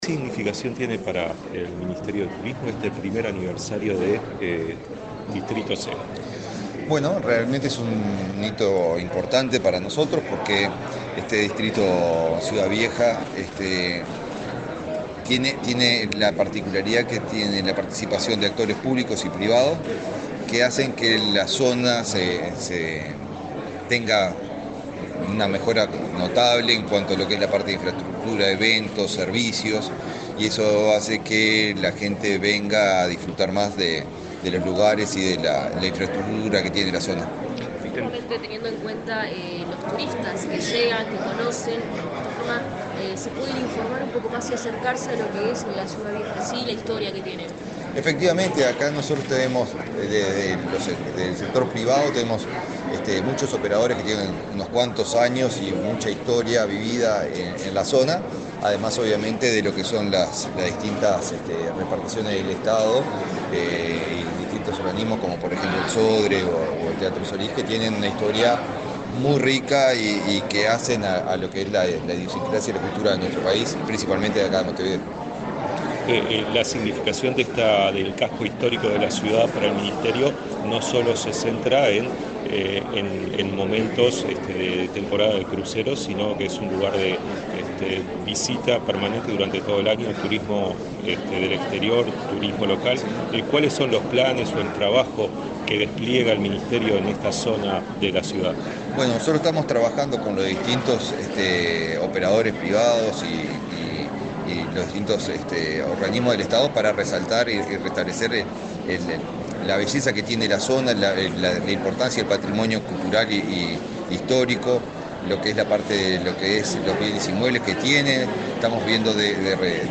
Declaraciones del ministro de Turismo, Eduardo Sanguinetti
Declaraciones del ministro de Turismo, Eduardo Sanguinetti 23/07/2024 Compartir Facebook X Copiar enlace WhatsApp LinkedIn El ministro de Turismo, Eduardo Sanguinetti, dialogó con la prensa, antes de participar en el primer aniversario de Distrito Ciudadela, celebrado este martes 23 en Montevideo.